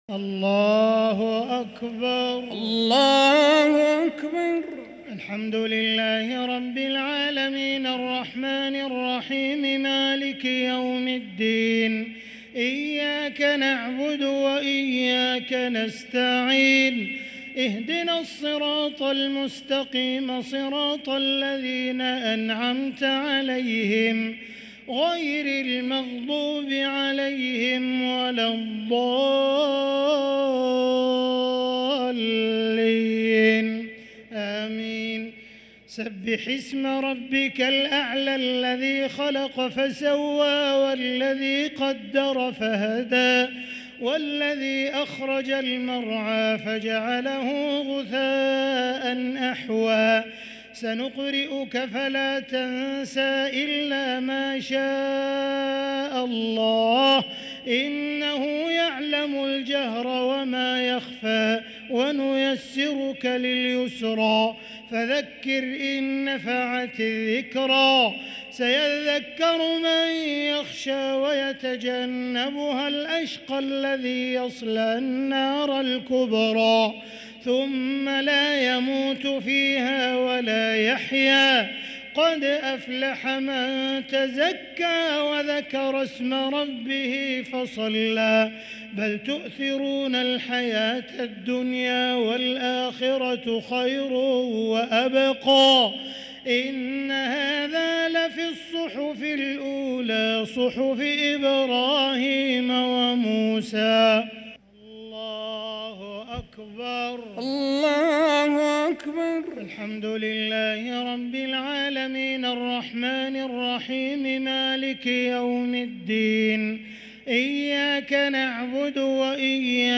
الشفع و الوتر ليلة 13 رمضان 1444هـ | Witr 13 st night Ramadan 1444H > تراويح الحرم المكي عام 1444 🕋 > التراويح - تلاوات الحرمين